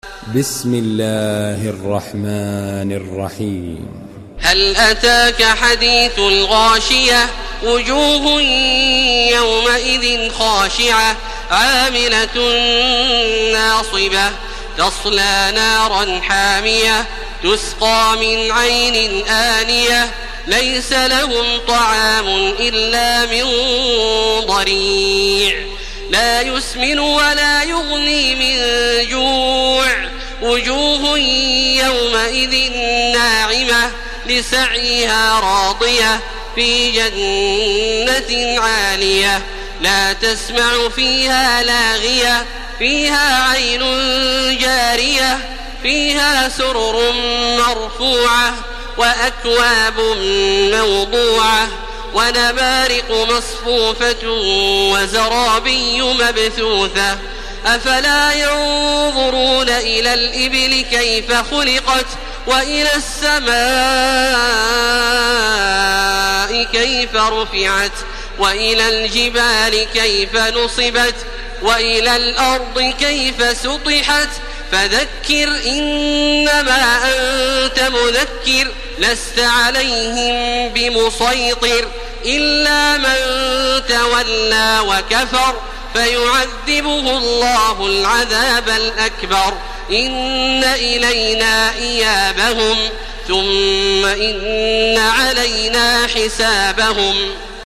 Surah Al-Ghashiyah MP3 by Makkah Taraweeh 1431 in Hafs An Asim narration.
Murattal Hafs An Asim